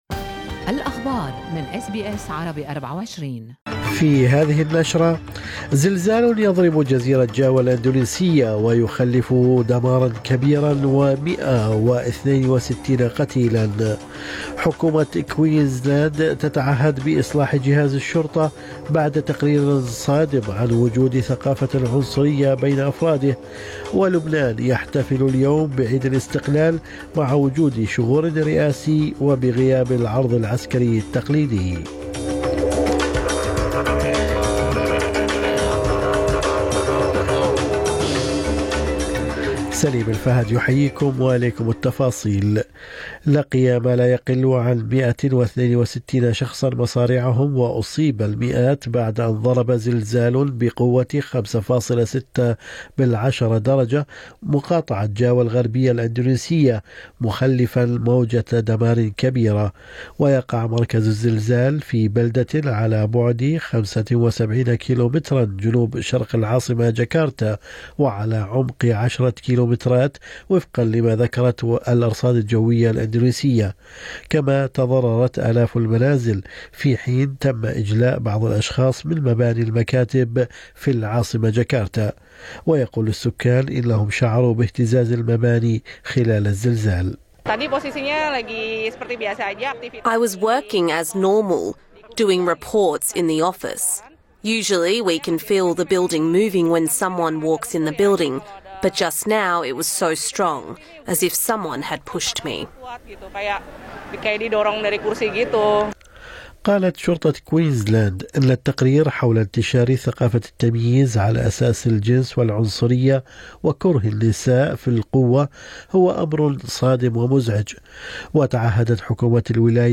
نشرة اخبار الصباح 22/11/2022